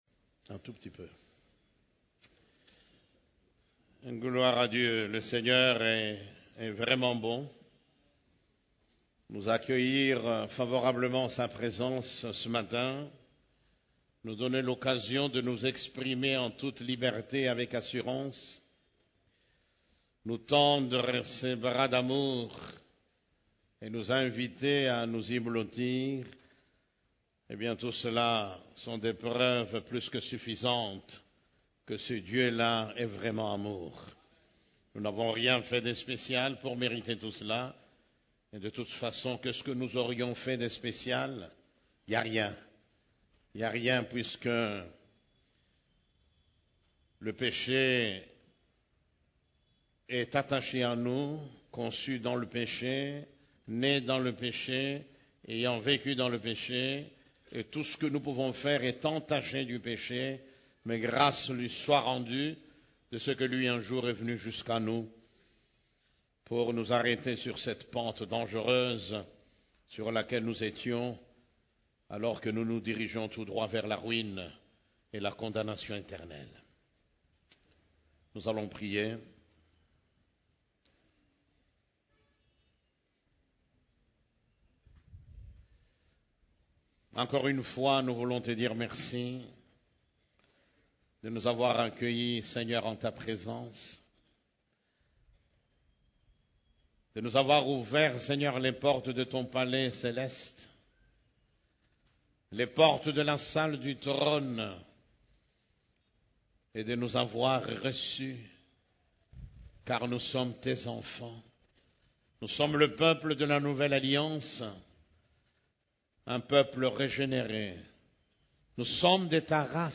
CEF la Borne, Culte du Dimanche, Le lieu secret où il fait bon d'être 3